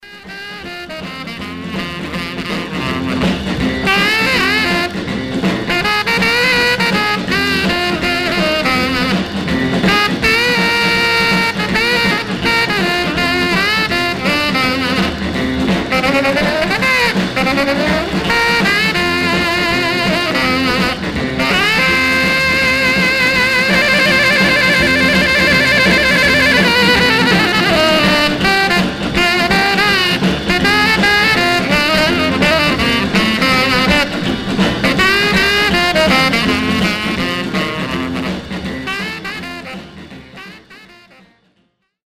Some surface noise/wear Stereo/mono Mono
R&B Instrumental